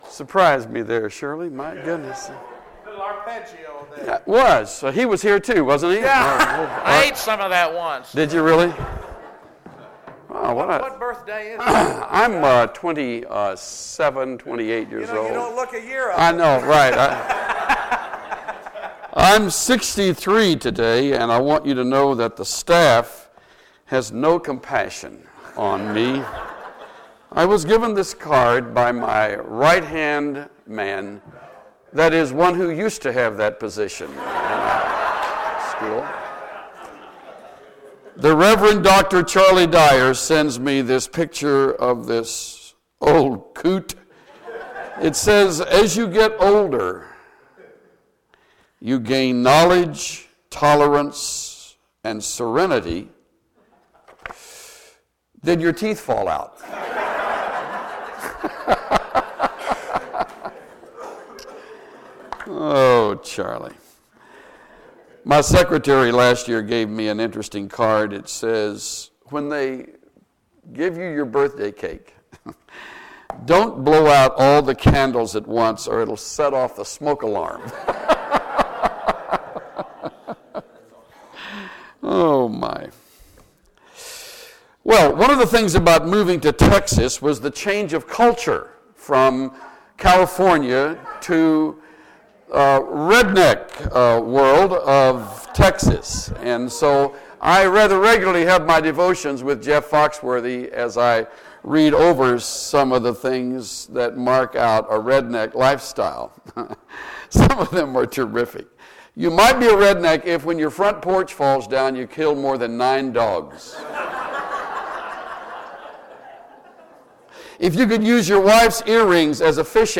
Chuck Swindoll talks about what we can learn from the Apostle Paul's leadership style found in 1 Thessalonians.